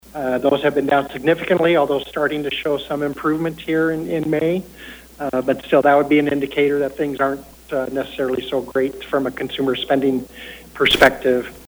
during a report to the state Transportation Commission yesterday (Tuesday).